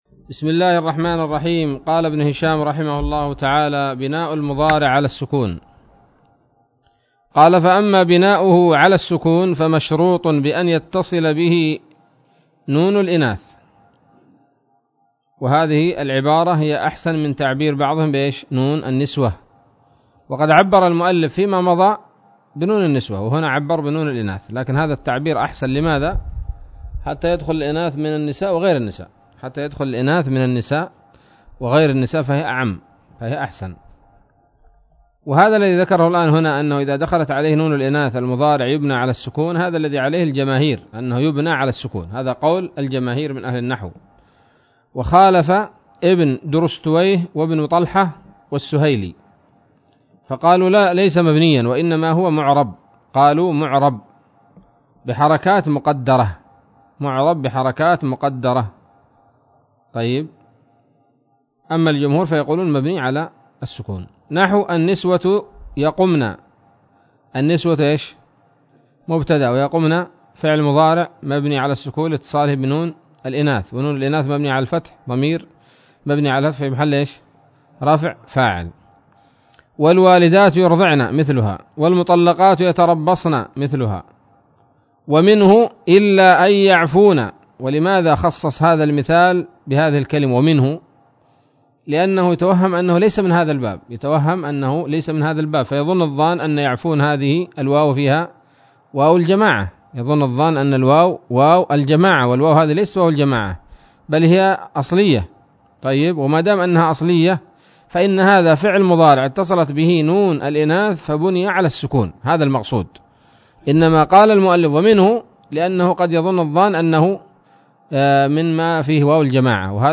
الدرس الثالث عشر من شرح قطر الندى وبل الصدى